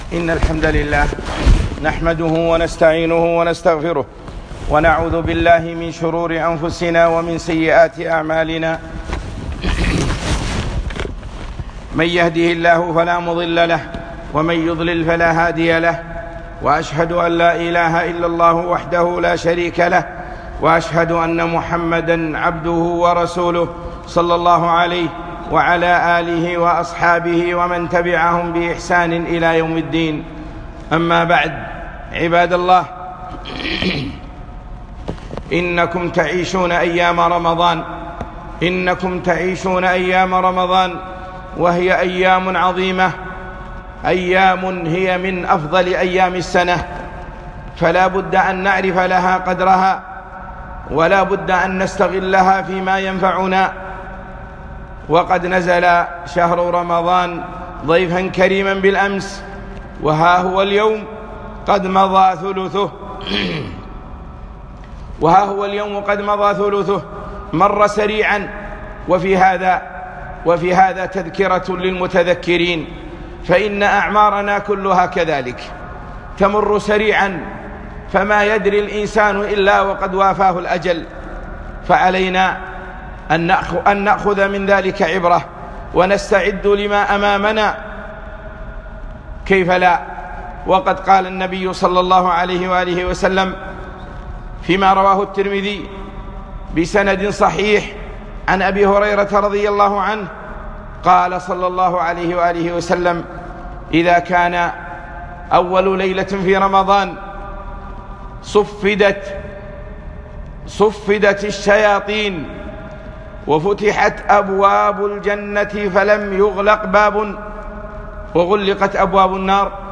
خطبة - فعل الخيرات وترك المحرمات في رمضان